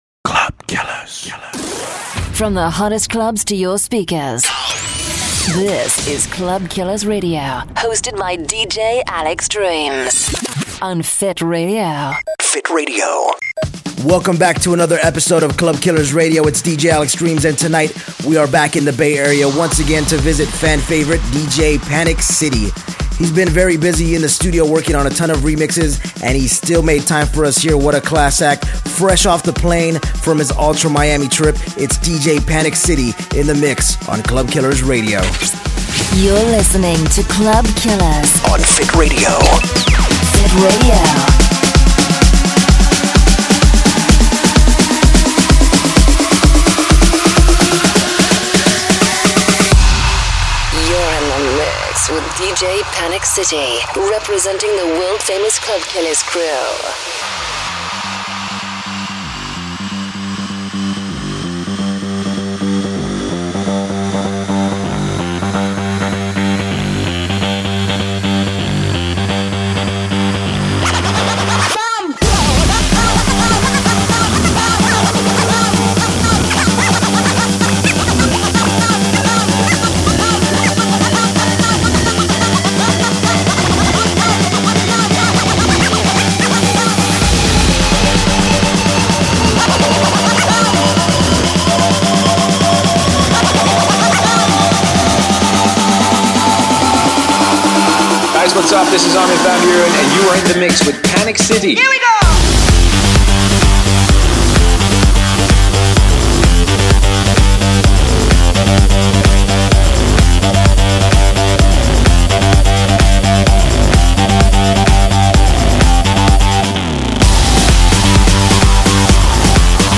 Guest DJ Mix
cutting edge club and dance floor anthems